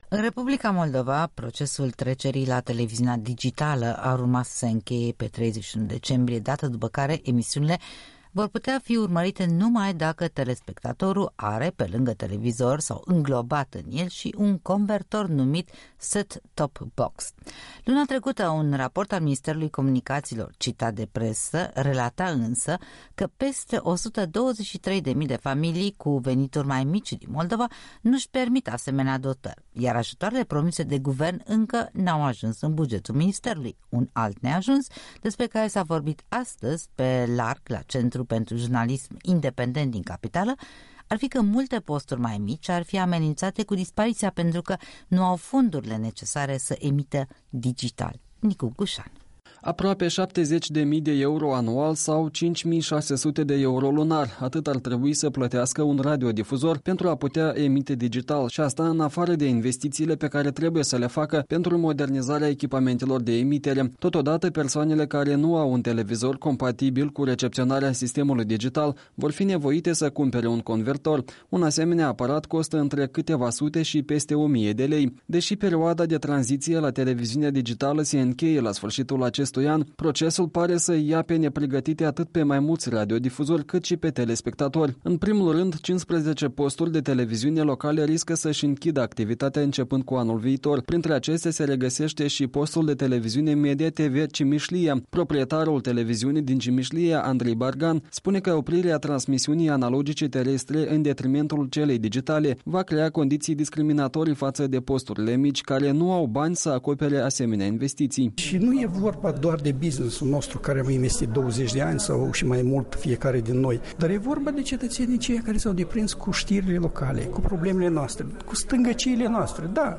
O dezbatere la Centrul pentru Jurnalism Independent de la Chișinău.